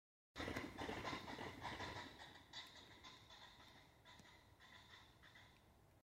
Faisán Plateado (Lophura nycthemera)